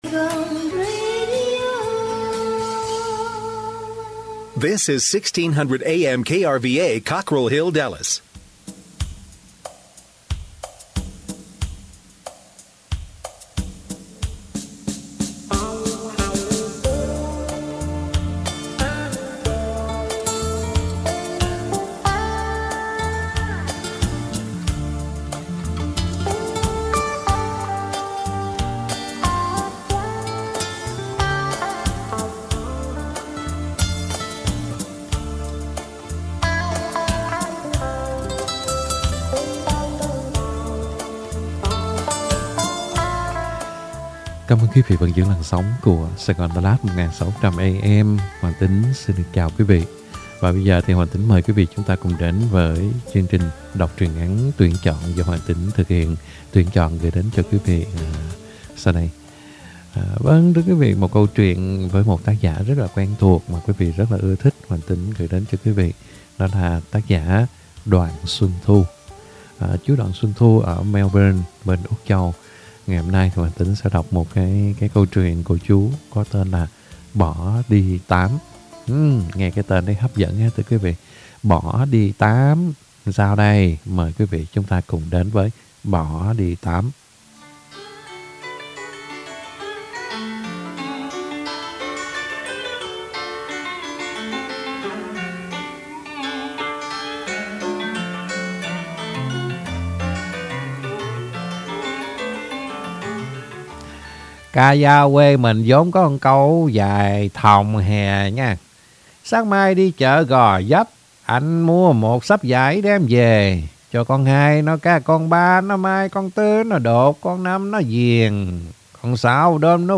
Đọc Truyện Ngắn = “ Bỏ đi Tám !!!